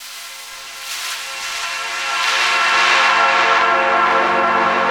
P A D61 01-L.wav